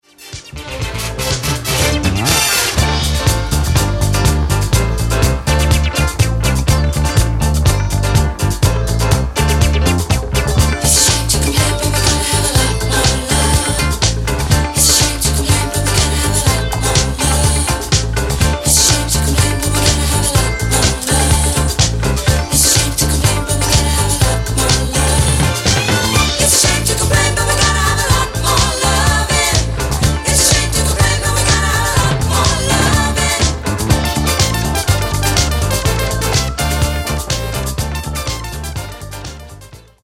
12''Mix Extended
Disco Funk e Dance Clssics degli anni 70 e 80.